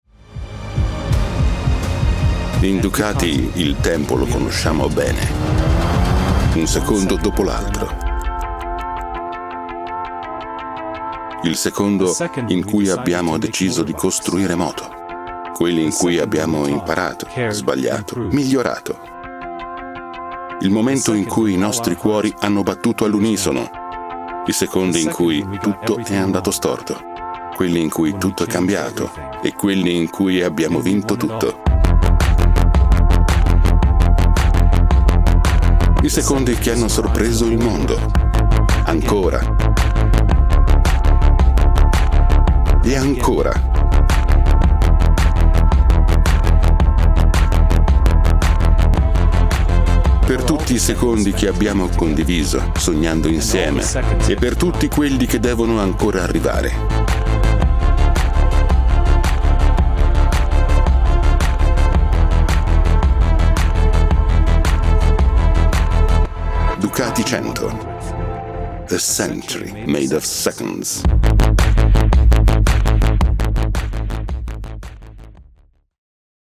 La mia voce è baritonale, calda e musicale: matura, chiara e naturale, con un tono accogliente ma deciso, ideale per progetti che richiedono credibilità e presenza.
Sprechprobe: Werbung (Muttersprache):